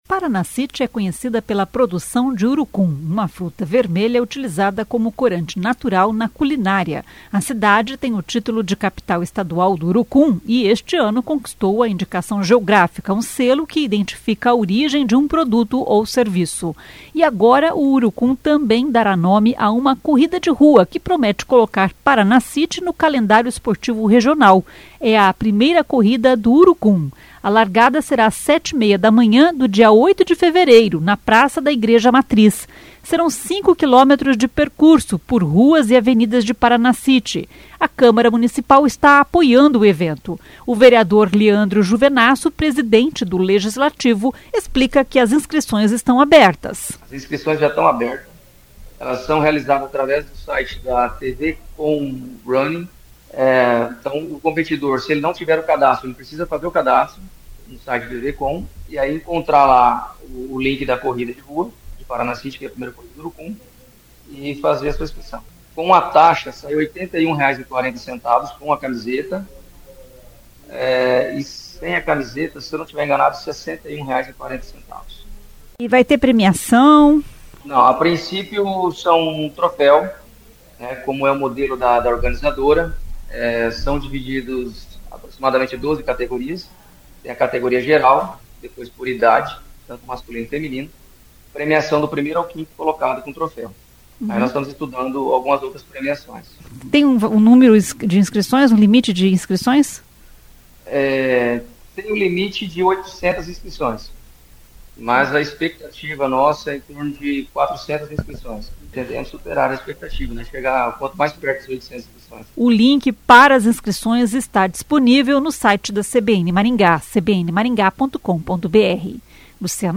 O vereador Leandro Juvenasso, presidente do Legislativo, explica que as inscrições estão abertas.